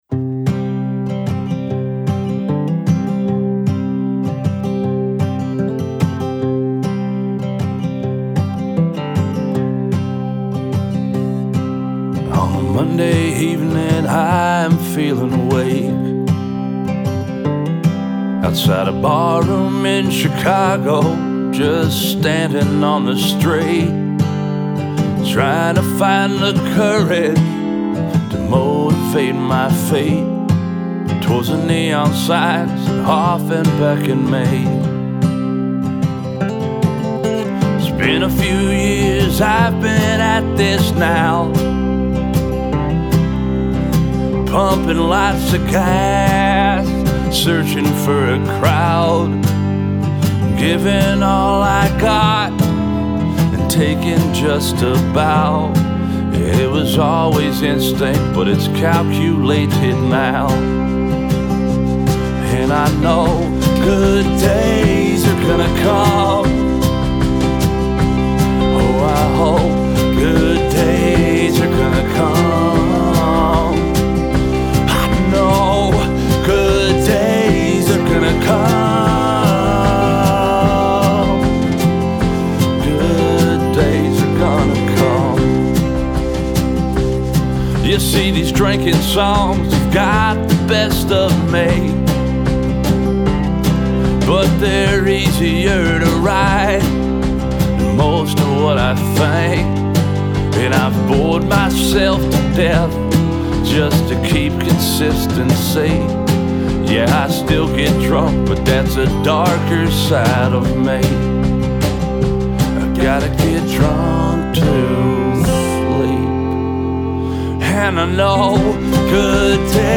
Good drinking music